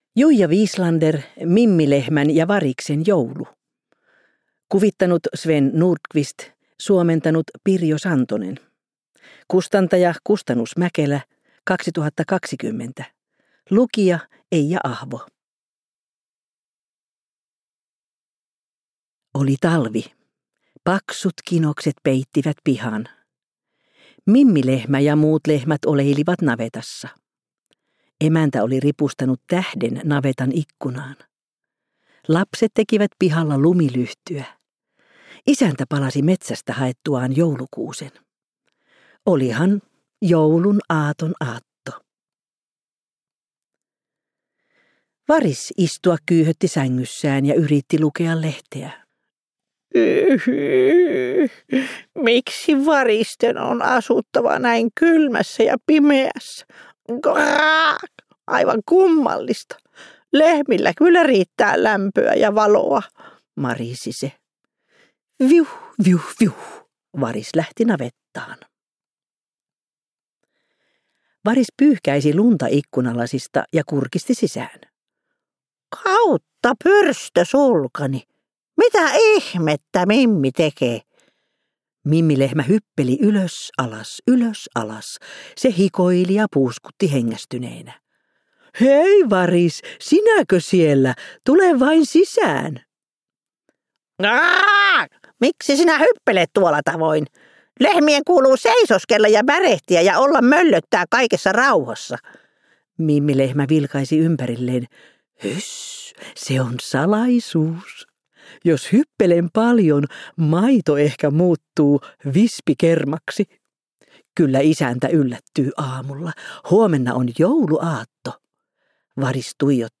Kuunneltavissa myös äänikirjana useissa eri äänikirjapalveluissa, lukijana Eija Ahvo.